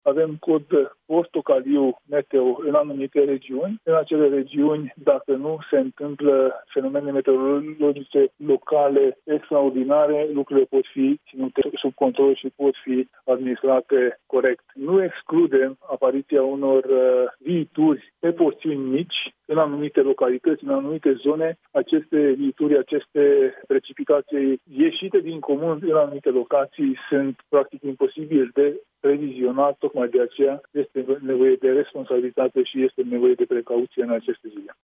Atenţie în următoarele zile în zona apelor curgătoare, unde există pericolul ca acestea să iasă din matcă, spune la Europa FM Barna Tánczos, ministrul Mediului, Apelor și Pădurilor.